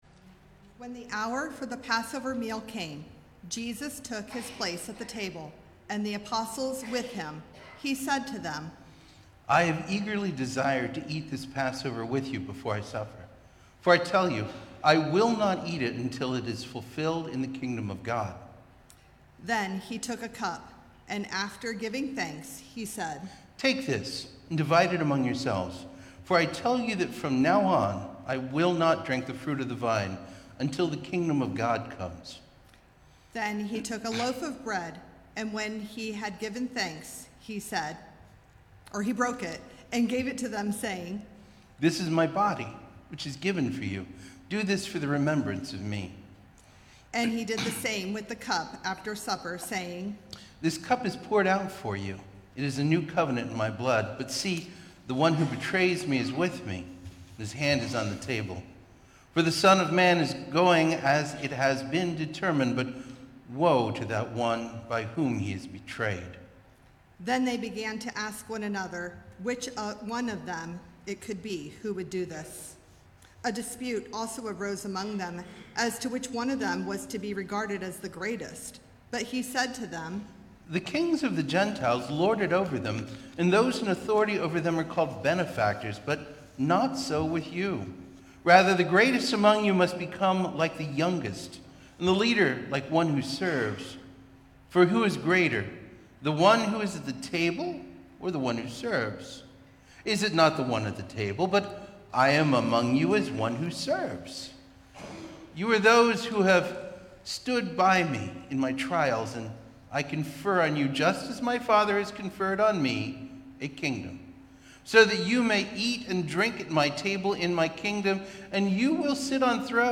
Passion-Reading-2025.mp3